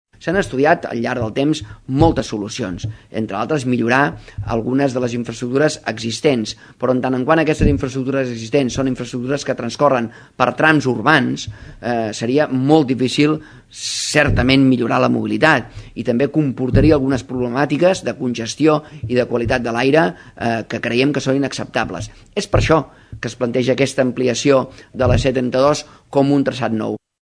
El conseller de Territori i Sostenibilitat, Damià Calvet, considera que les alternatives a l’allargament de la C-32 entre Tordera i Lloret de Mar  comportarien “problemàtiques de congestió i de qualitat de l’aire”. En una entrevista a l’ACN, Calvet ha recordat que hi ha altres projectes paral·lels a l’allargament de l’autopista, però passen per “trams urbans” i per això “seria molt difícil millorar la mobilitat”.